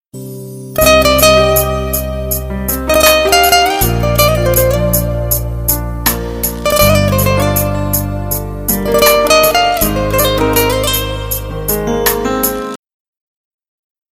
Play, download and share taralalala original sound button!!!!
romantic_guita2r.mp3